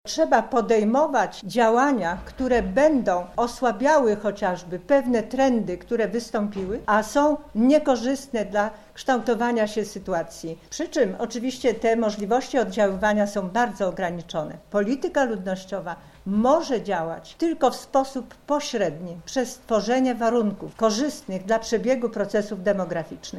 Dyskutowano o tym dzisiaj na konferencji w Lubelskim Urzędzie Wojewódzkim.
-mówi Przemysław Czarnek, Wojewoda lubelski